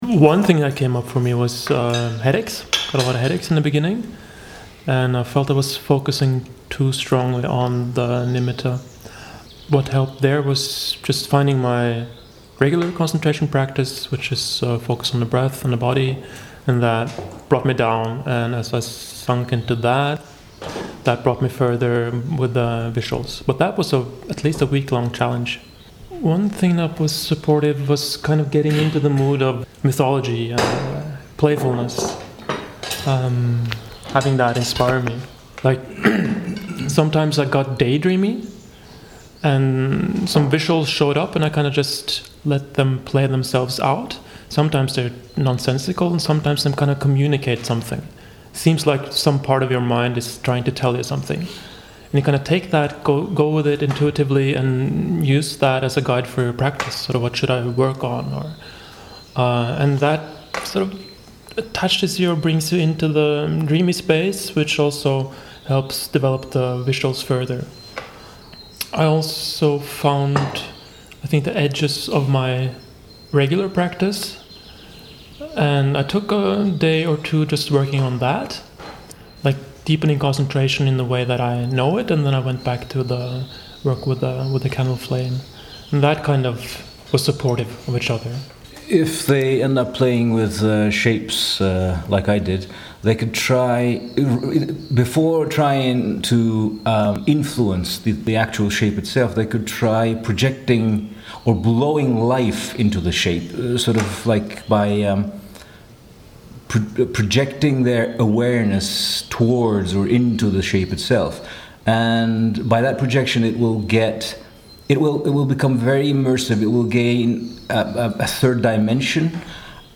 It was recorded in the kitchen at the end of a meal, so there are some sounds of dishes and people cleaning up.